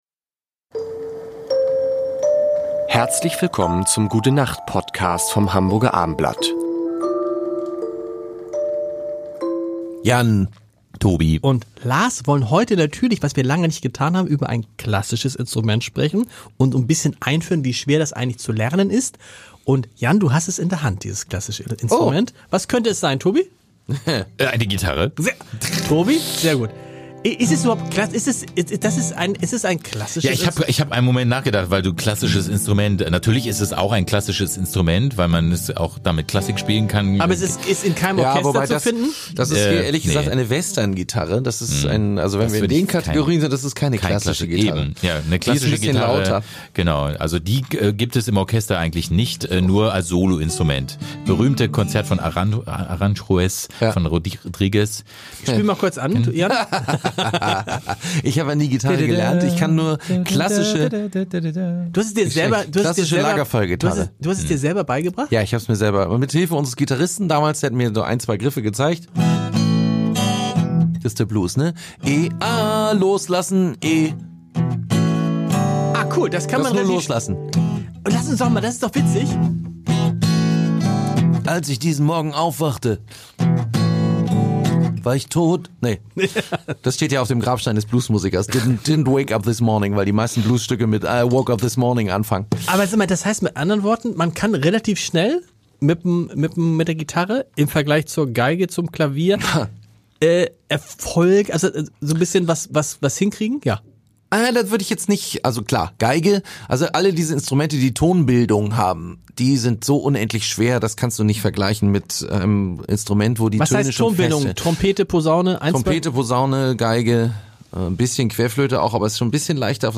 und natürlich sehr musikalische fünf Minuten.
gelacht und philosophiert, und Stargäste sind auch dabei.